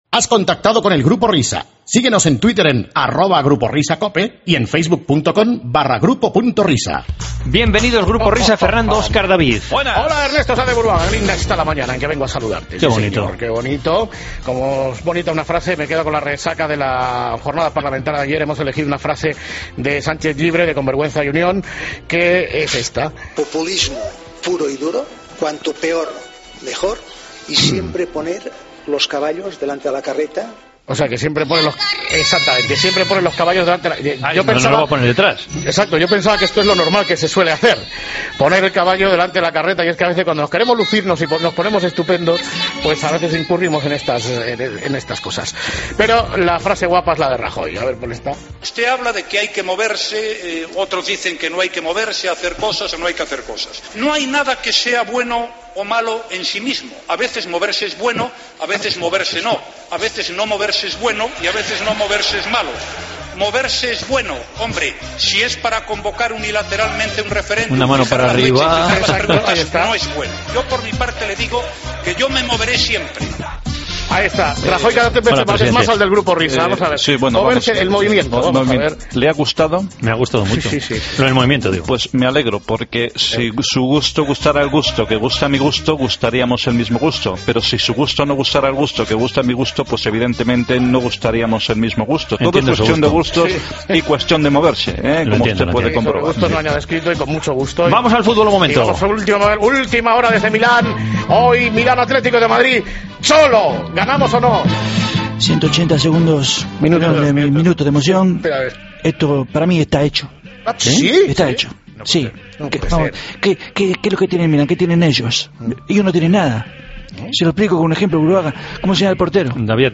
AUDIO: Dos perlas sacadas del Senado...